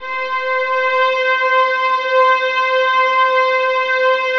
VIOLINS .2-L.wav